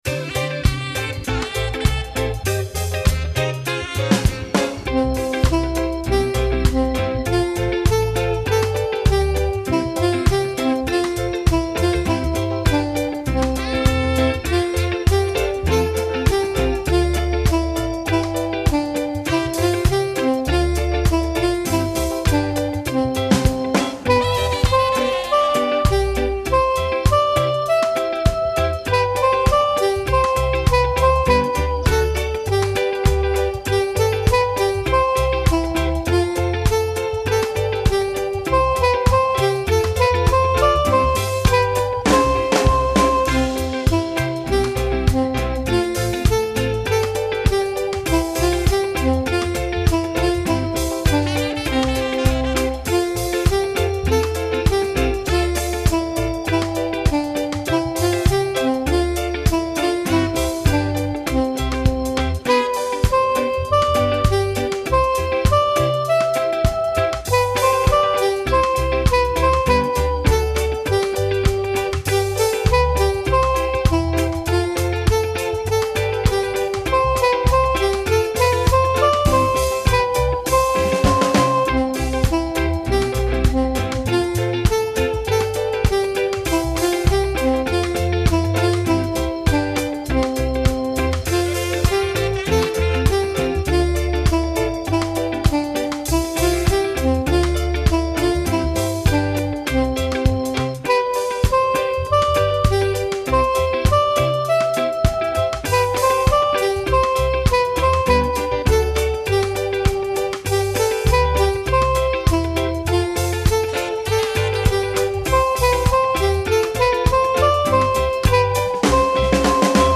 This is a very old Easter hymn from the fourteenth century and first noted in English translation in the eighteenth.
For no particular reason, other than BIAB’s less than brilliant grasp of church organ, my version is somewhat reggae.